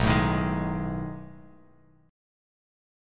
B13 Chord
Listen to B13 strummed